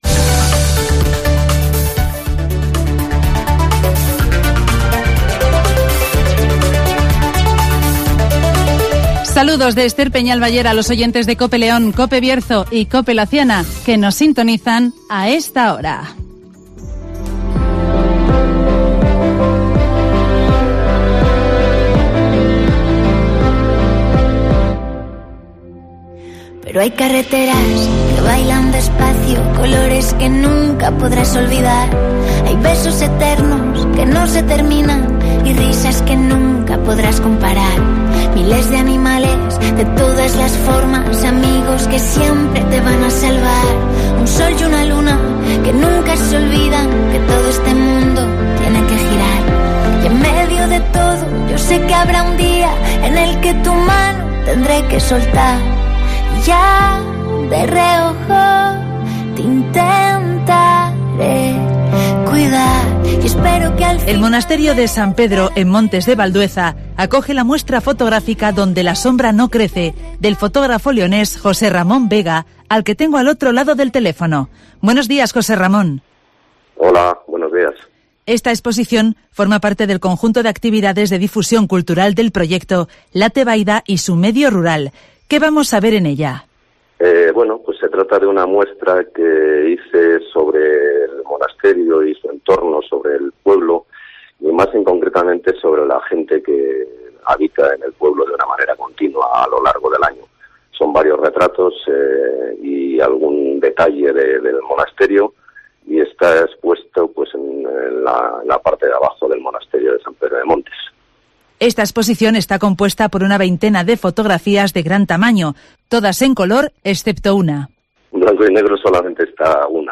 El monasterio de Montes de Valdueza acoge la muestra fotográfica Donde la sombra no crece (Entrevista